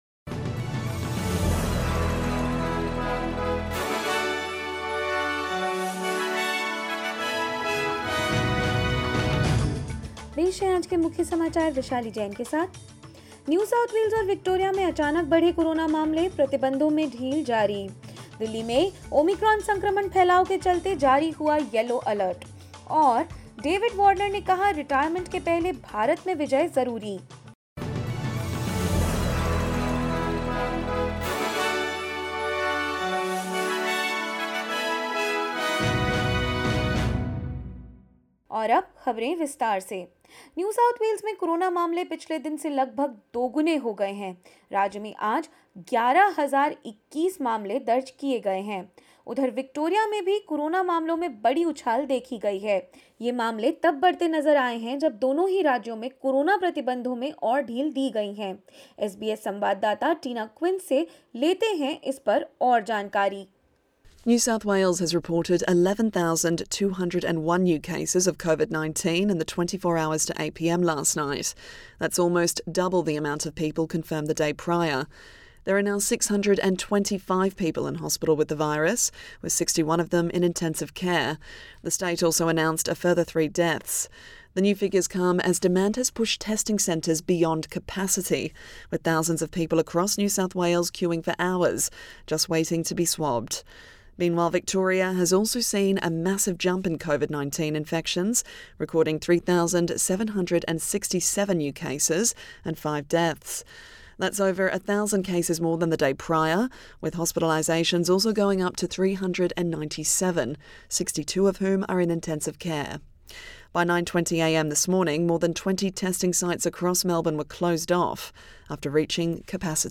SBS Hindi News 29 December 2021: Daily COVID-19 cases tally almost doubles in NSW
2912_hindi_news.mp3